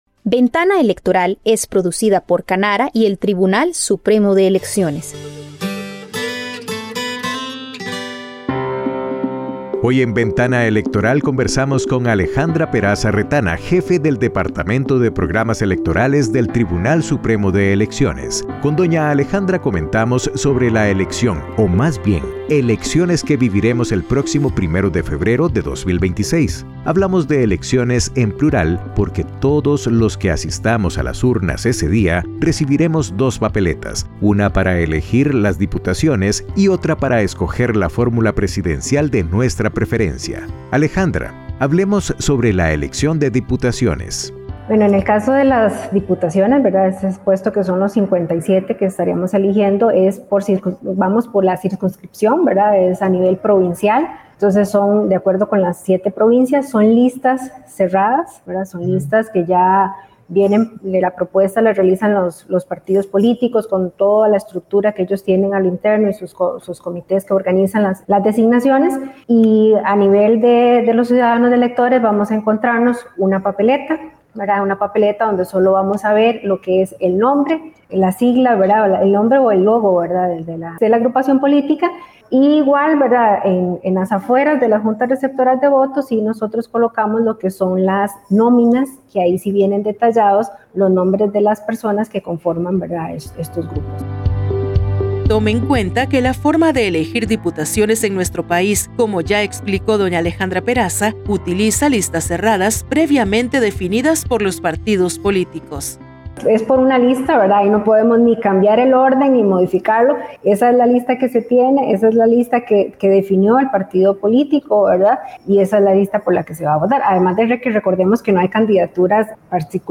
Cápsulas